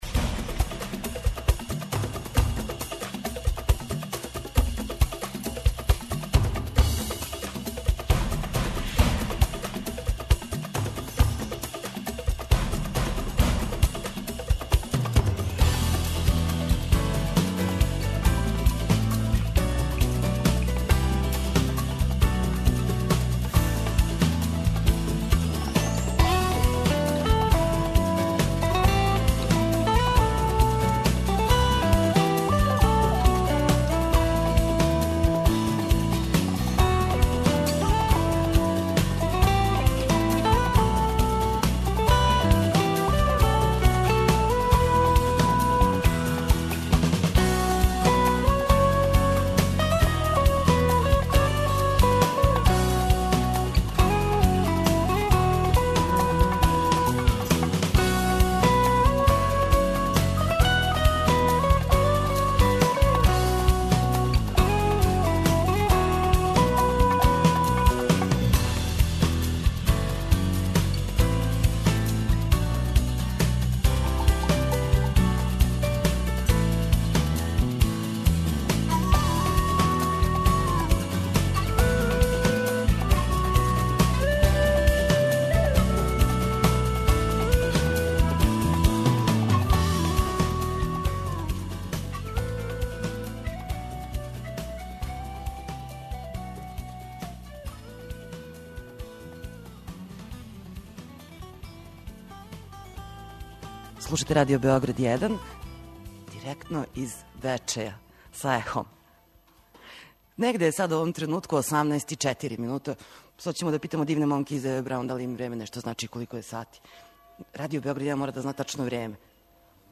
Емисија је посвећена саставу Ева Браун, чији су чланови и гости емисије, која се емитује уживо из Бечеја.